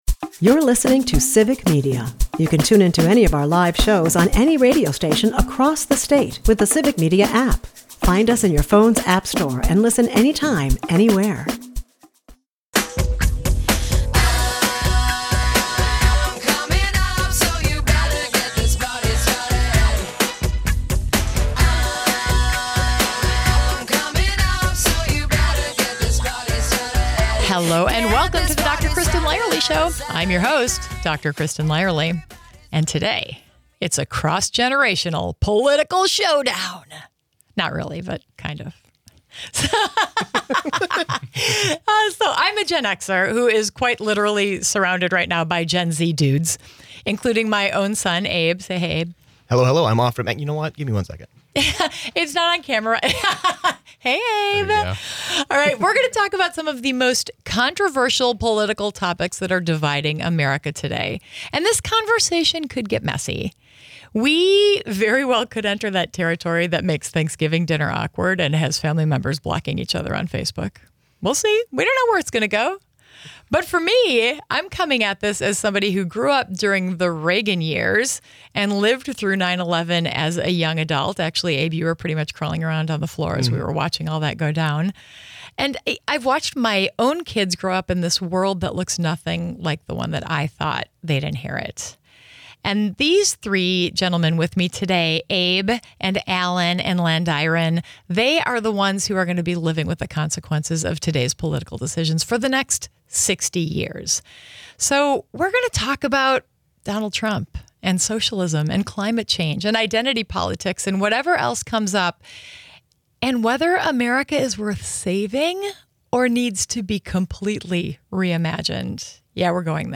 The political conversation America needs but doesn't want to have. What happens when a Gen X mom sits down with three Gen Z men to debate the most explosive political issues tearing America apart? You get raw, unfiltered discussion about Trump, socialism, identity politics, and whether this country is worth saving or needs to be burned down and rebuilt.